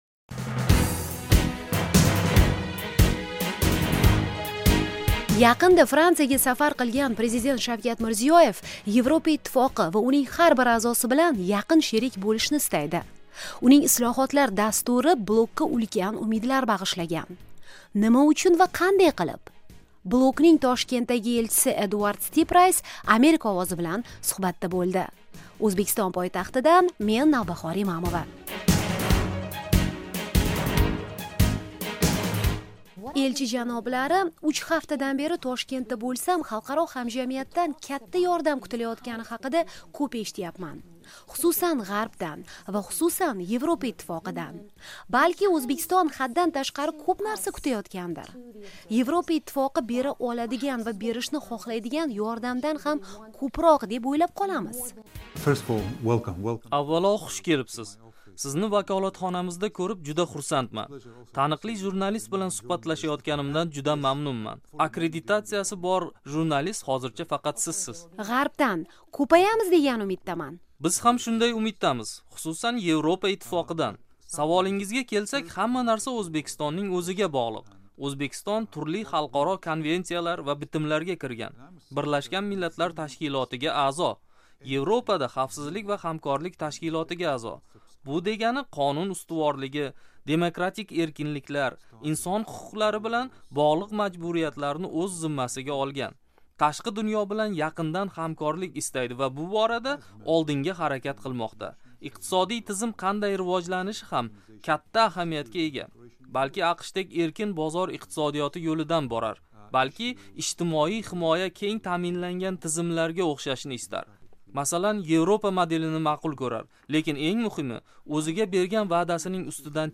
Yevropa Ittifoqining O'zbekistondagi elchisi bilan Toshkentda suhbat